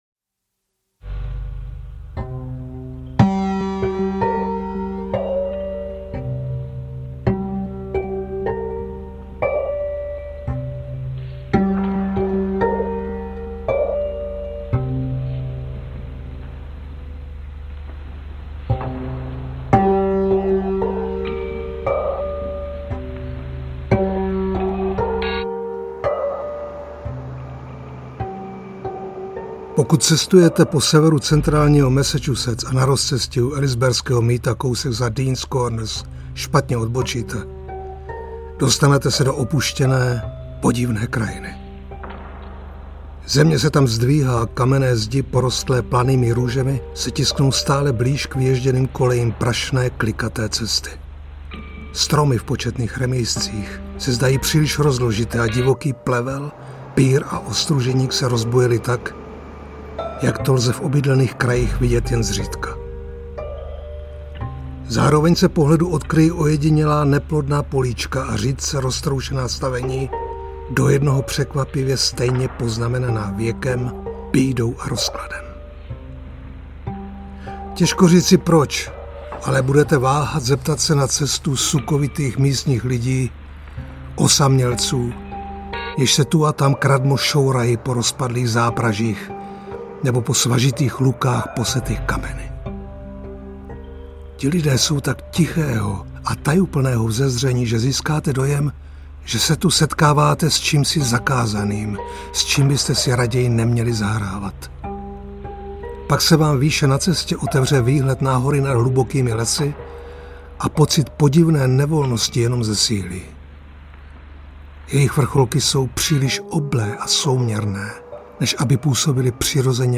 Hrůza v Dunwichi audiokniha
Ukázka z knihy
• InterpretMiroslav Krobot